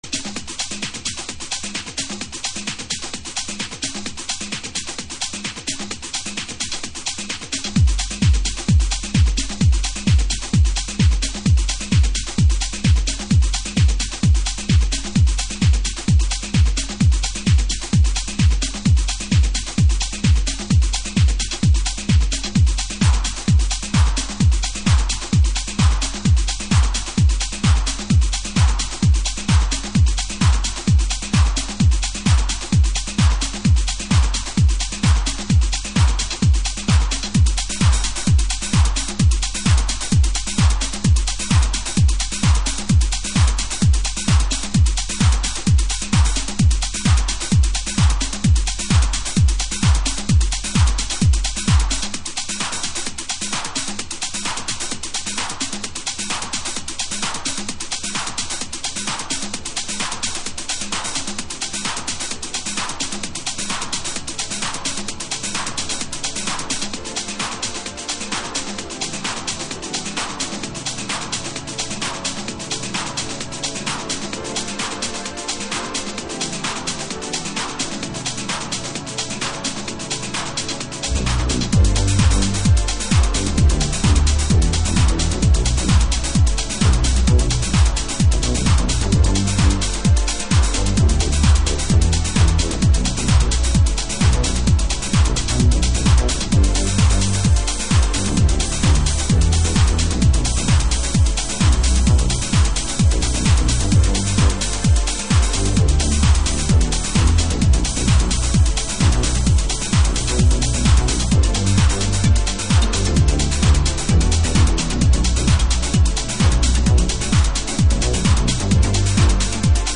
House / Techno
ローカルでテクノが生まれていたスコットランド生まれ（現オランダ在住）による直球ポスト・デトロイトテクノトラック。